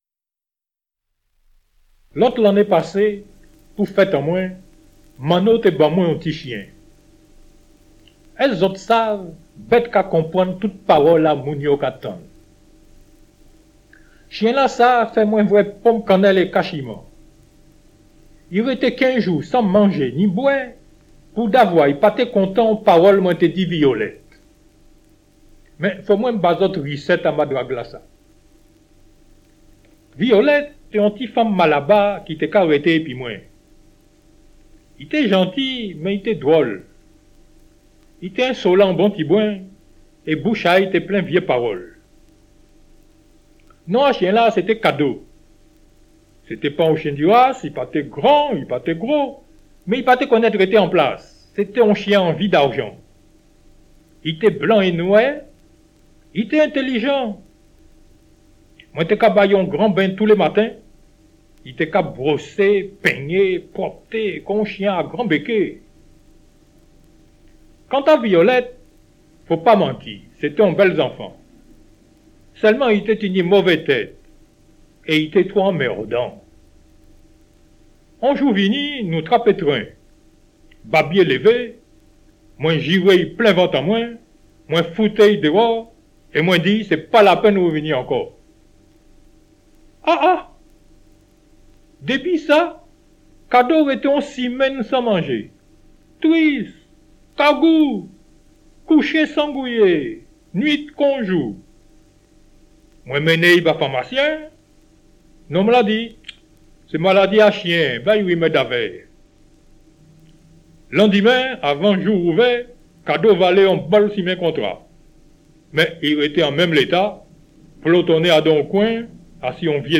Le disque vinyle
écrits et lus par l'auteur